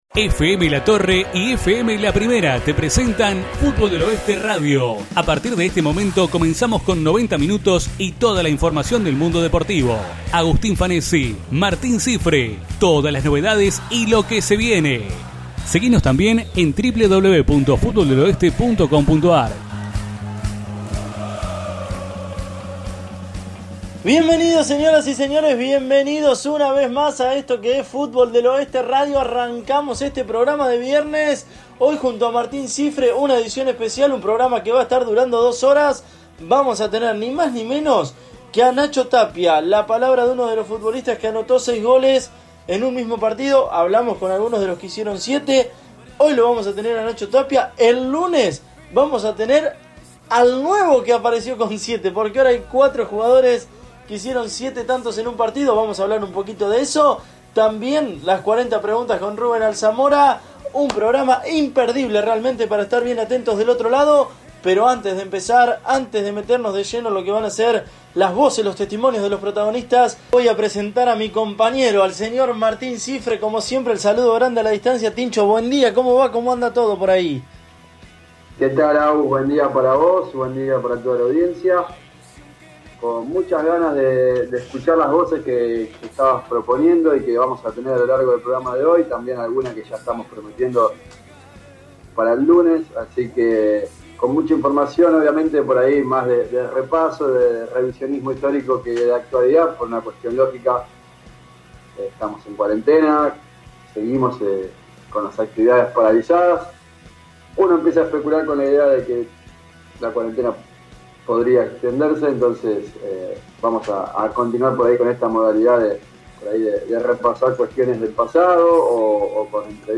Nuestro programa radial tuvo su 16ª edición de este 2020, aquí te acercamos los 2 bloques para que puedas escucharlo a través de tu computadora o dispositivo móvil.